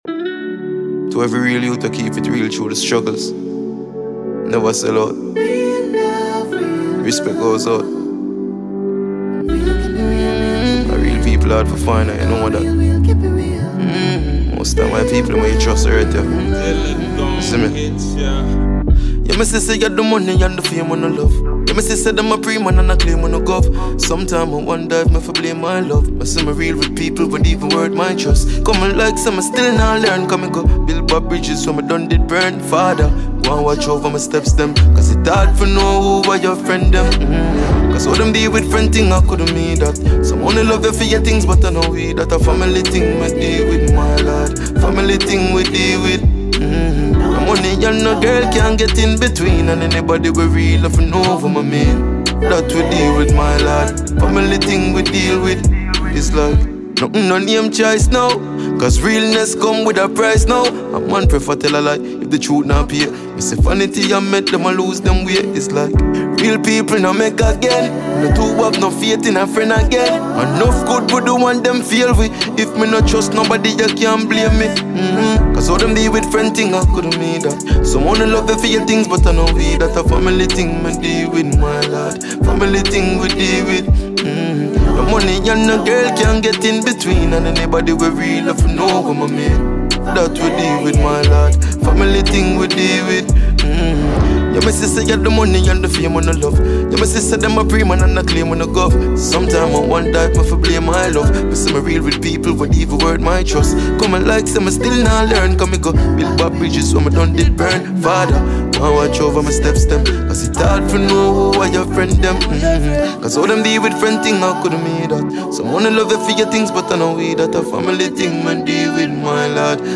Dancehall
heartfelt reggae/dancehall track
giving listeners an uplifting and infectious vibe.
• Genre: Reggae / Dancehall